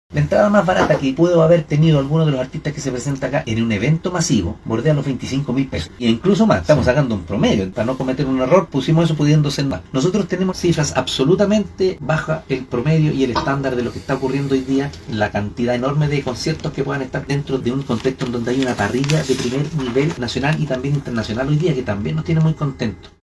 02-ALCALDE-Entradas-bajas-para-evento-de-primer-nivel-1.mp3